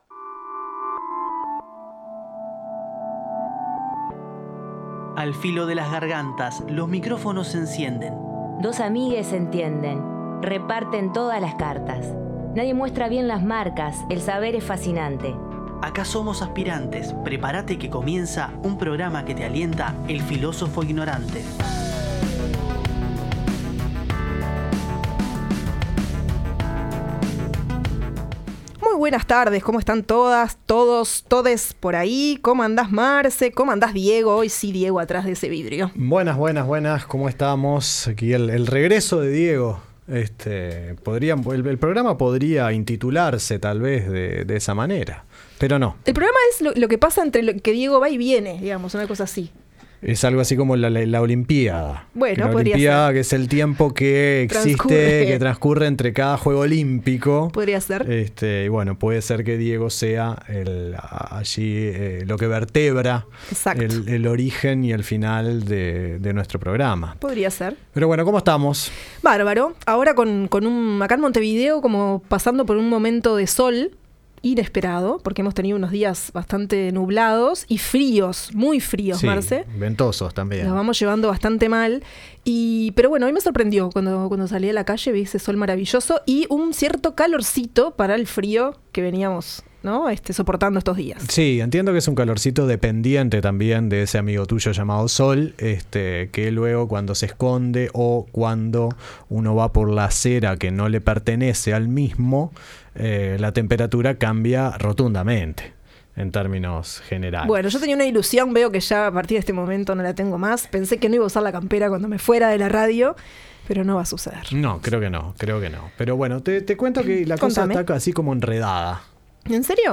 En el programa número 17 de El Filósofo Ignorante volvimos a disfrutar de un segmento que nombramos como «Pico a pico» y en el que nos damos el gusto de conversar con personas de diferentes lugares sobre distintos y variados temas.